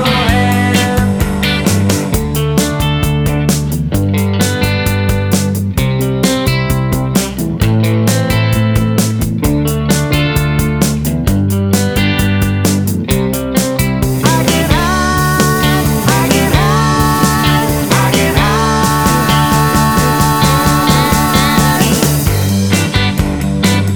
No Guitars Pop (1960s) 2:30 Buy £1.50